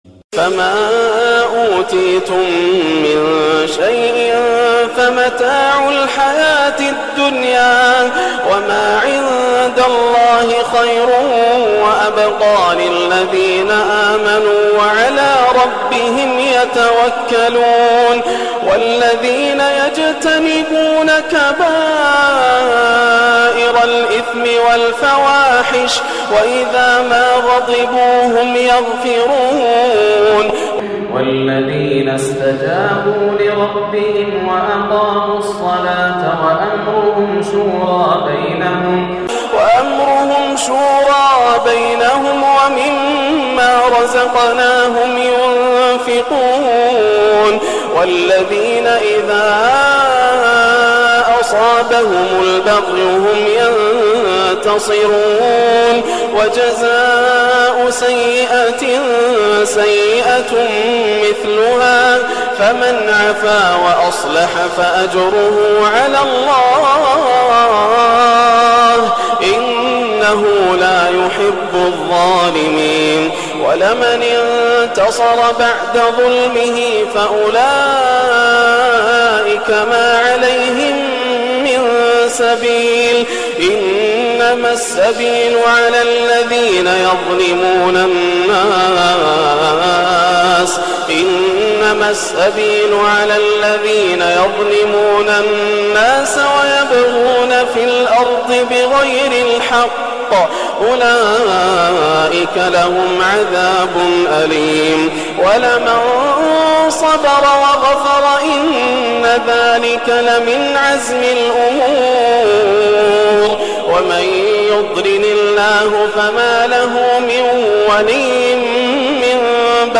(والذين يجتنبون كبائر الإثم والفواحش) - إبدااااع من صلاة العشاء > عام 1430 > الفروض - تلاوات ياسر الدوسري